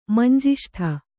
♪ manjiṣṭha